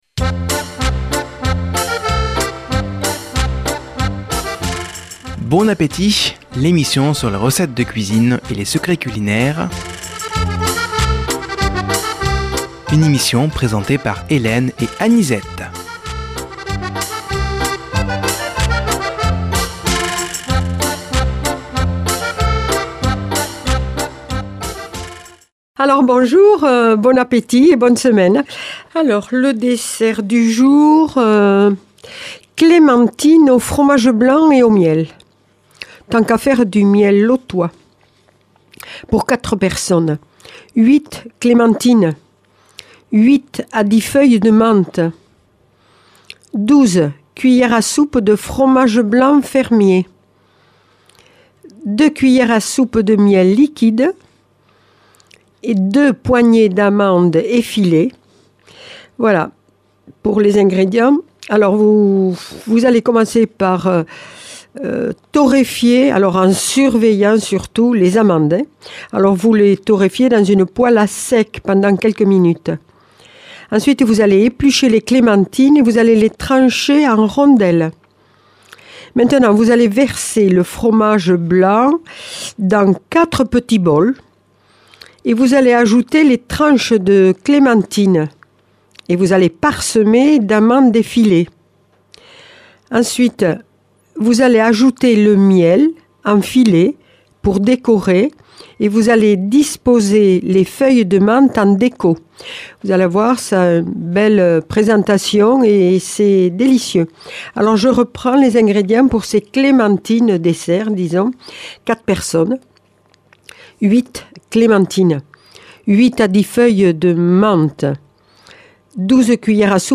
Présentatrices